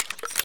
reload_gl.ogg